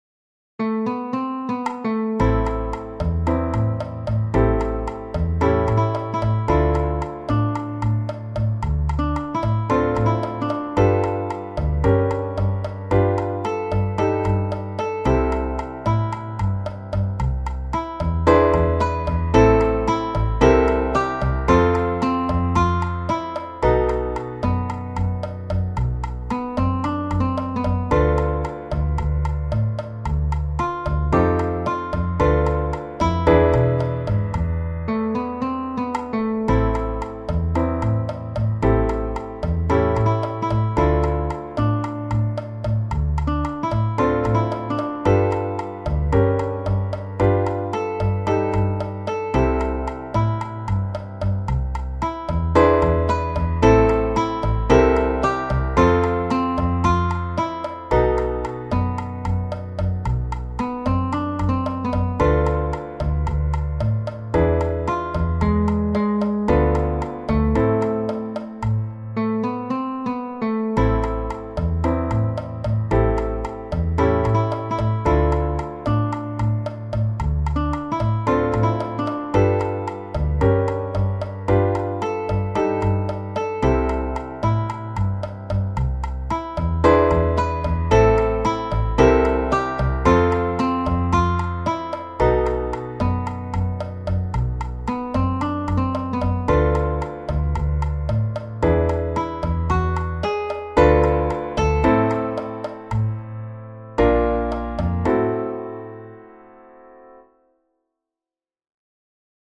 Genere: Moderne